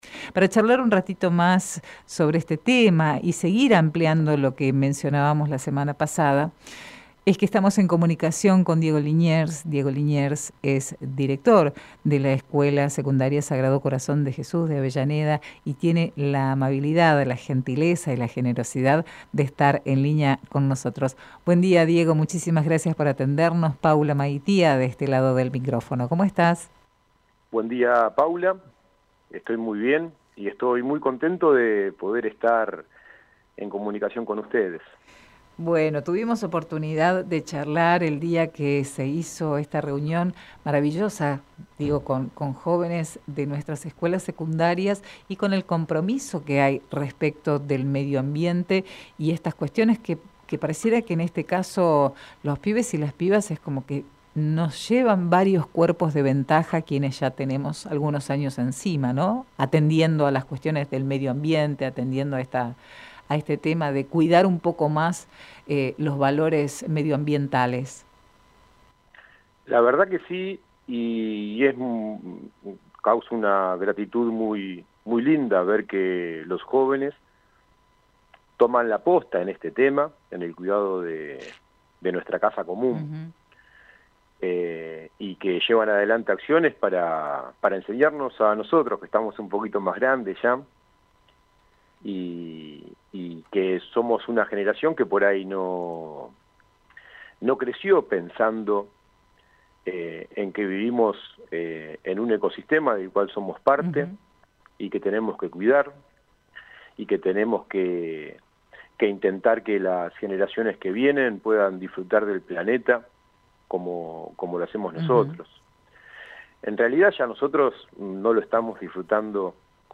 Compartimo entrevista